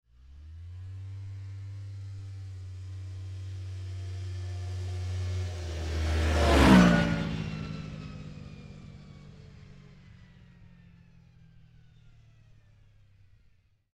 На этой странице собраны звуки снегохода: рев мотора, скрип снега под гусеницами, свист ветра на скорости.
Автомобиль для снега едет издалека в обратную сторону